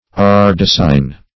Search Result for " ardassine" : The Collaborative International Dictionary of English v.0.48: Ardassine \Ar*das"sine\, n. [F. (cf. Sp. ardacina), fr. ardasse a kind of silk thread, fr. Ar.